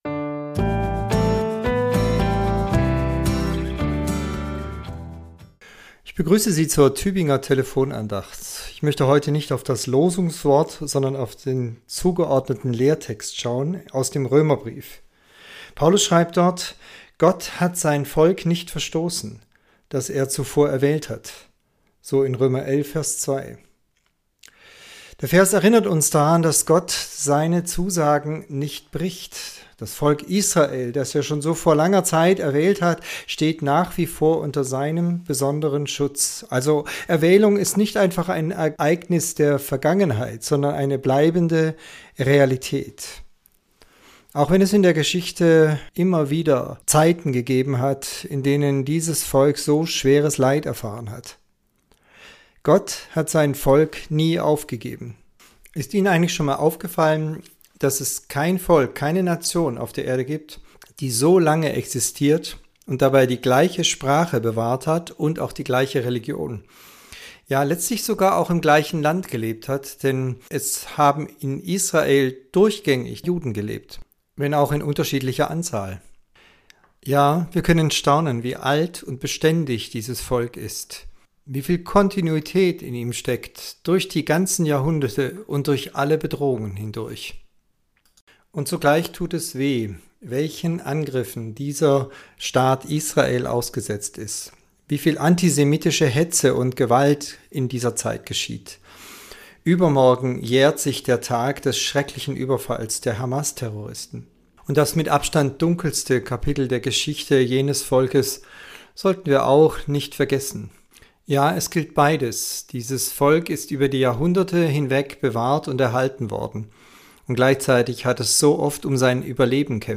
Andacht zur Tageslosung